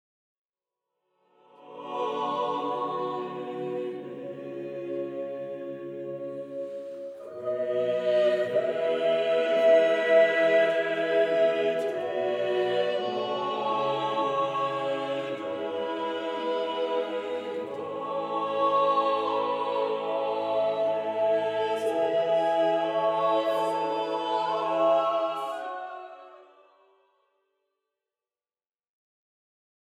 Messe à quatre voix - Messa a quattro voci
Format :MP3 256Kbps Stéréo